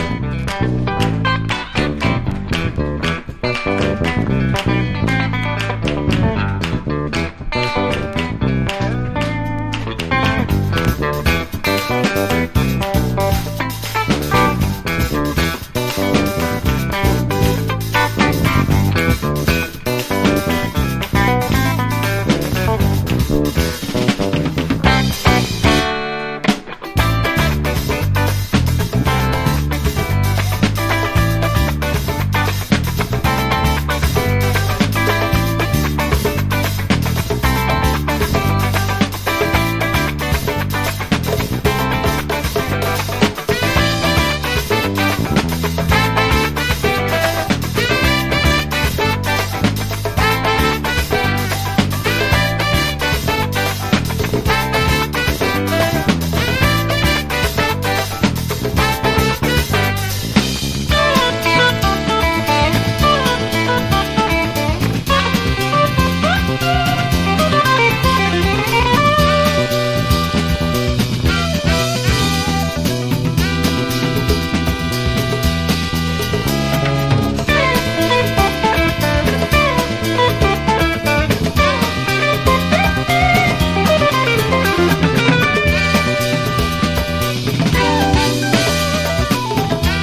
RARE GROOVE